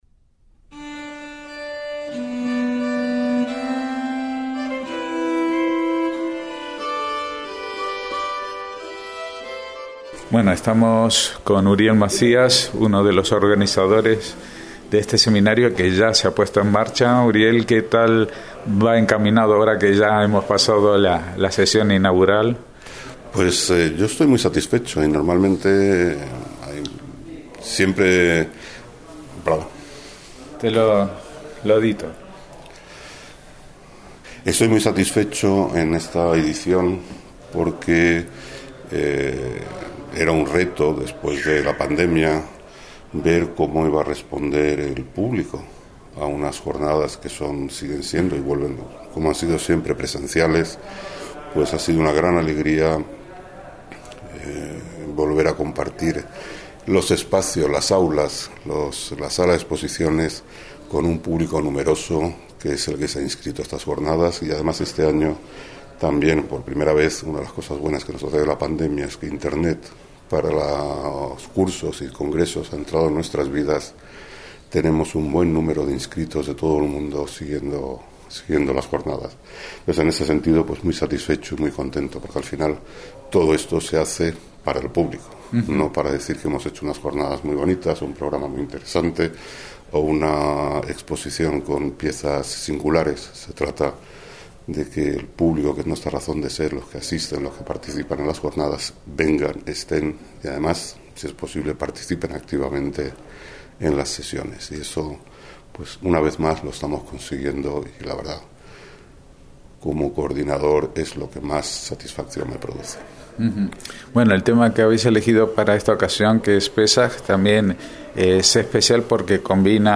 entrevistar